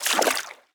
Footstep_Water_06.wav